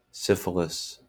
IPA/ˈsɪfɪlɪs/